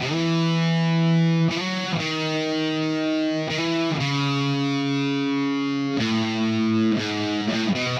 Power Pop Punk Guitar 04.wav